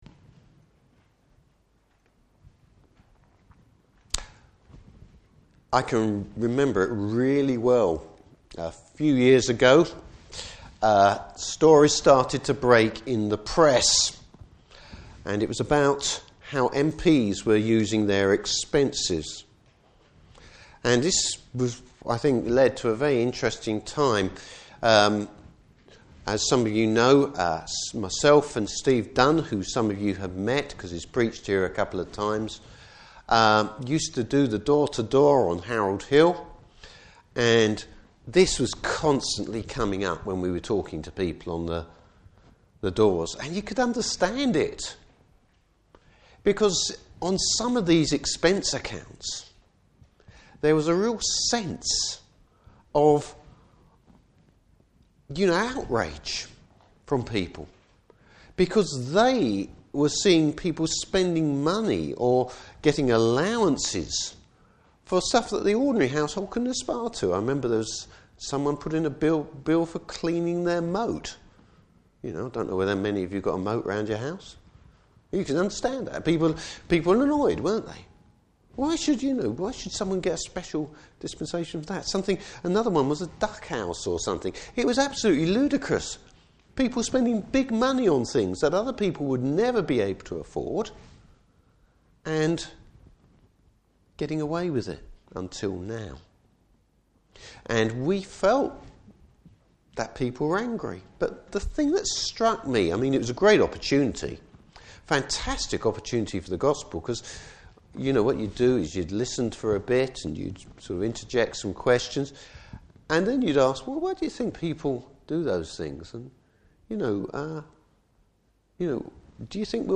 Series: New Years Sermon.
Service Type: Morning Service David's faith rejects human pragmatism.